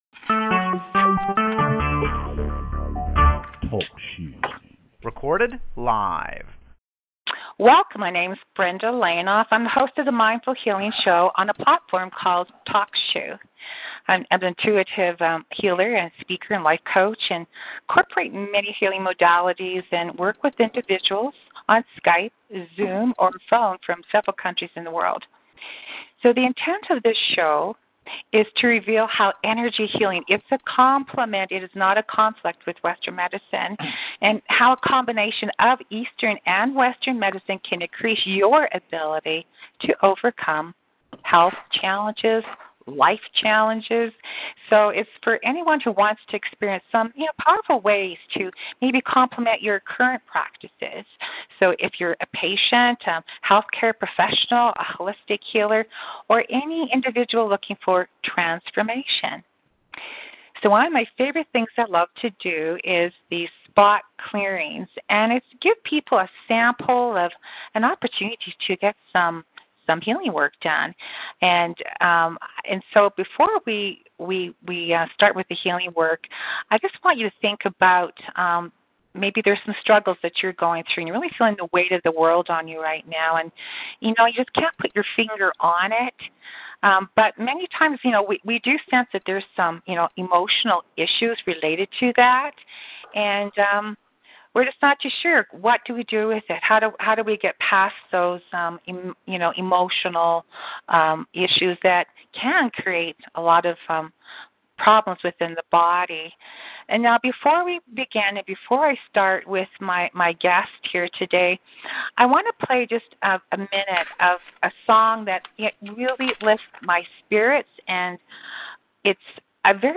Recording of recent energetic spot reading clearing Physical Pain and releasing Money Blocks on